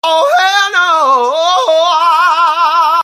oh-hell-no-vine-5_tzem2rij.mp3